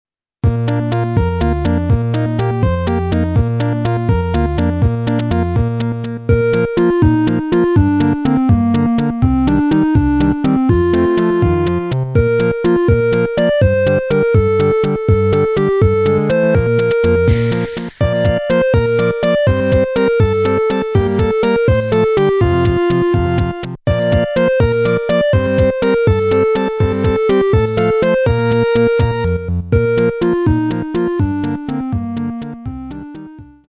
ゲームのENDっぽい。